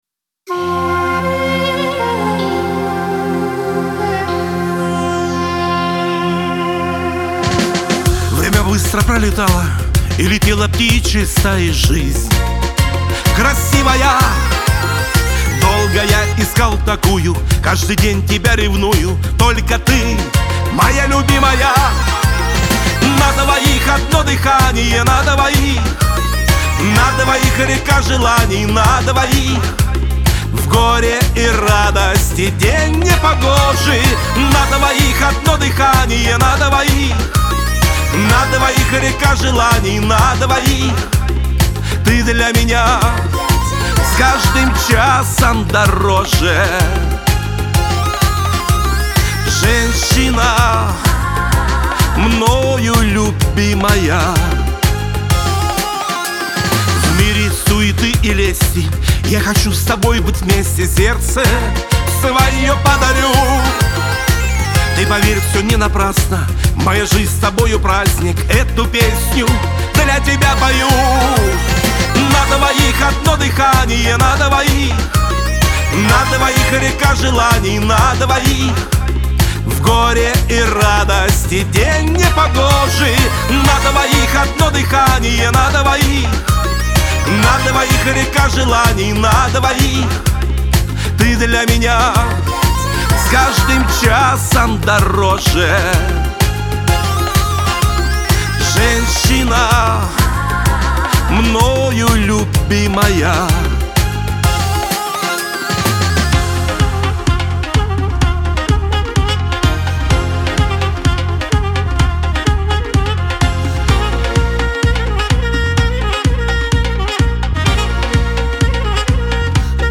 Шансон , Лирика